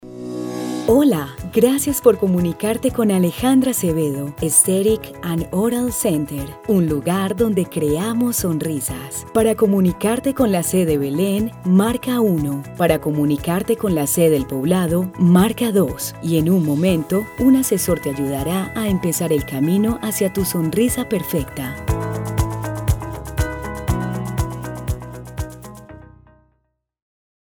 conoce mi voz
VOICE OVER